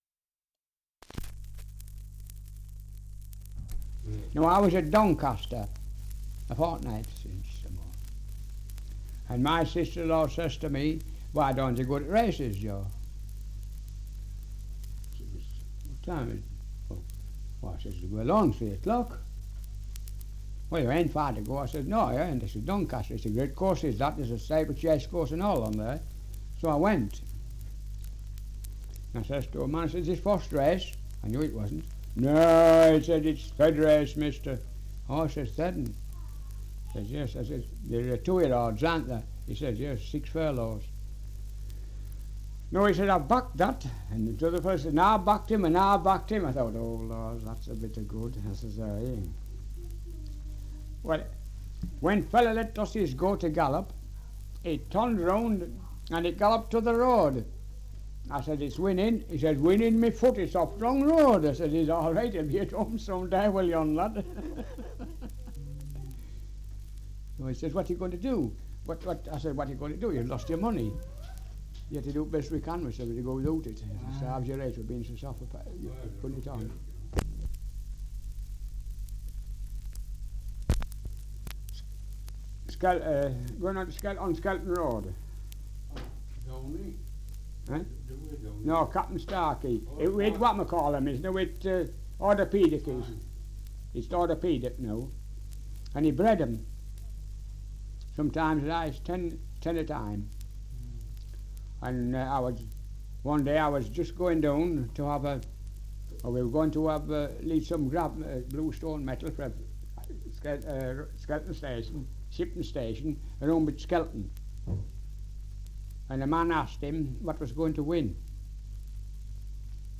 Survey of English Dialects recording in York, Yorkshire
78 r.p.m., cellulose nitrate on aluminium